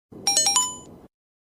notification.mp3